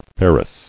[fer·rous]